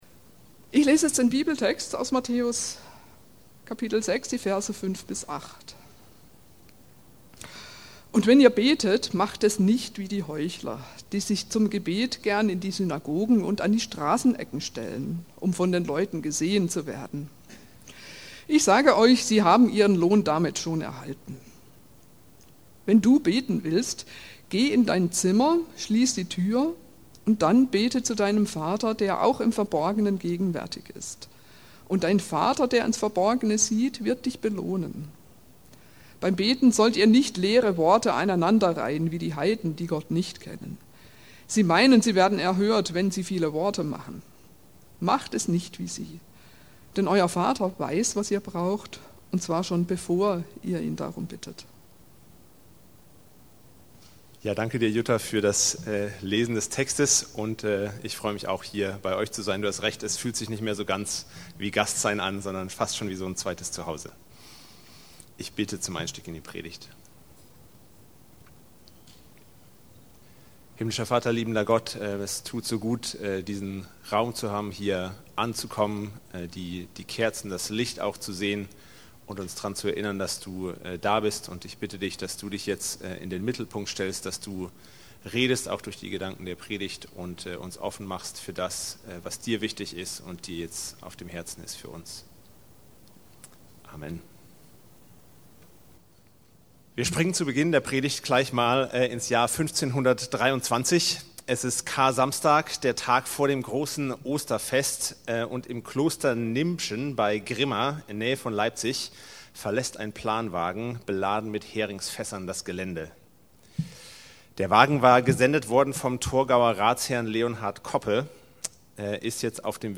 5 Zugänge zu Gott“KBP Predigtserie zur Fastenzeit 25 gemeinsam mit Projekt:KirchePredigt